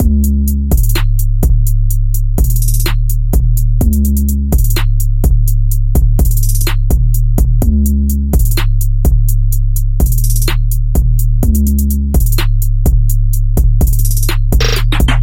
基本陷阱节拍钢琴
Tag: 126 bpm Trap Loops Piano Loops 2.56 MB wav Key : C